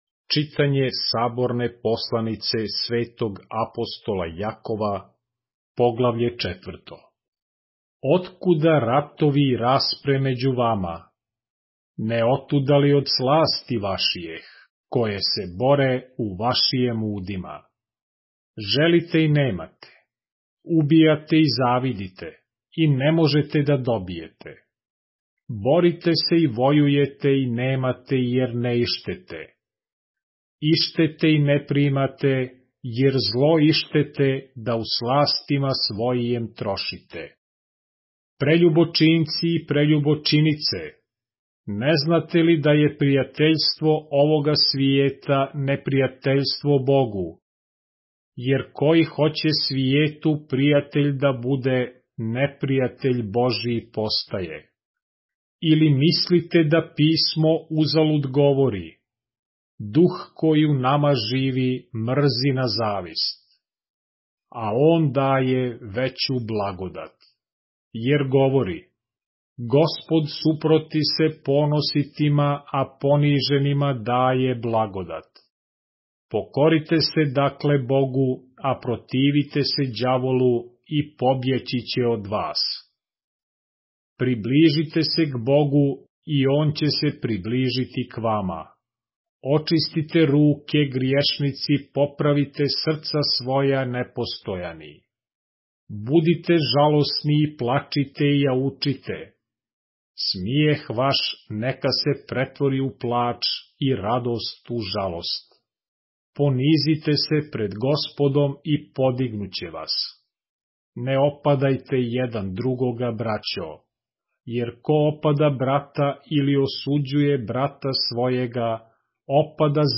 поглавље српске Библије - са аудио нарације - James, chapter 4 of the Holy Bible in the Serbian language